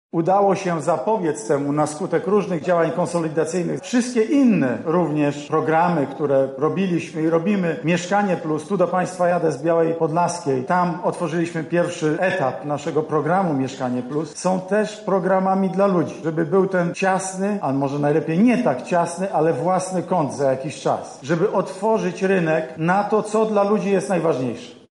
Premier Mateusz Morawiecki spotkał się z mieszkańcami Lublina